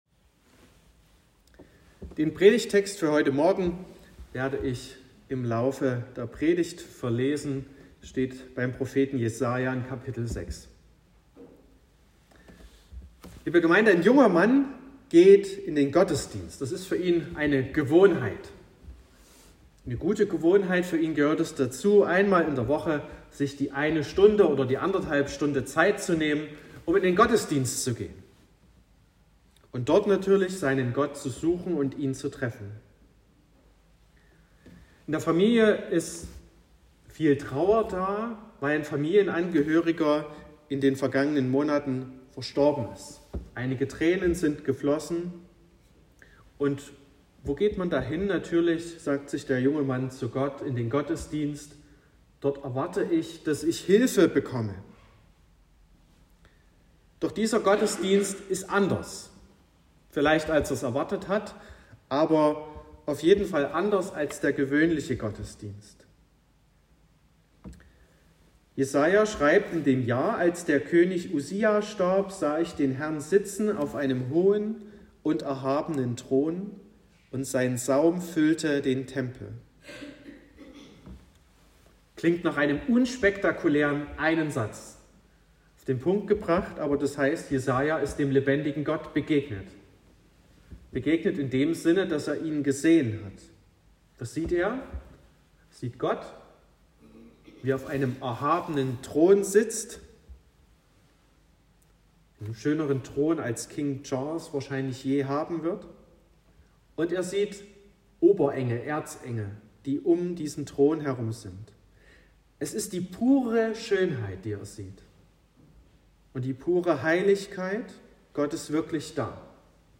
04.06.2023 – Gottesdienst
Predigt und Aufzeichnungen